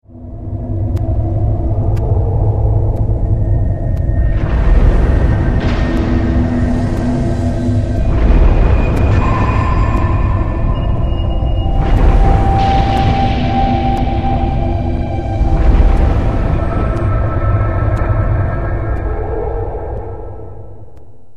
thunder
thunder.mp3